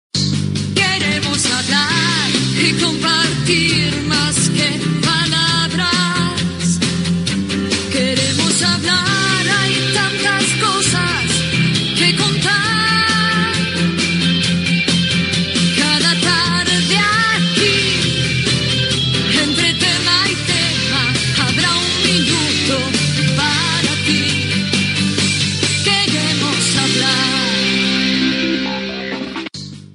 Sintonia cantada del programa